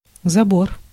Ääntäminen
IPA : /fɛns/